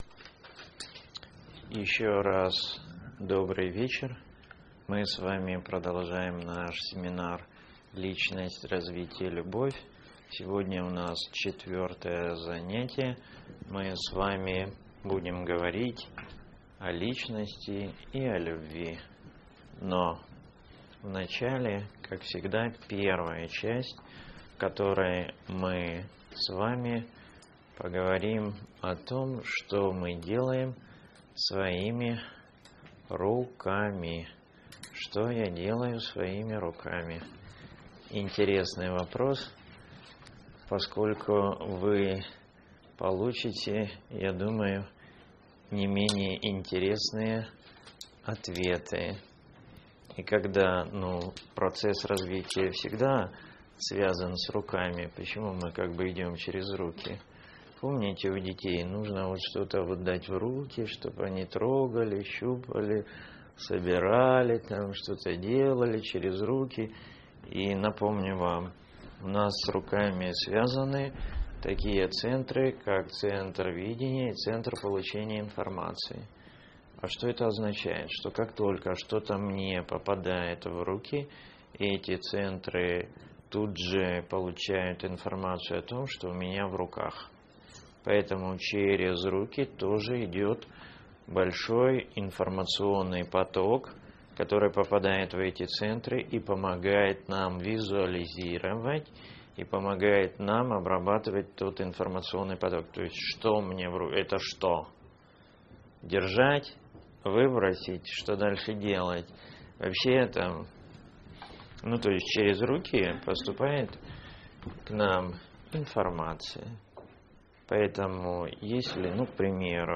Лекции Семинар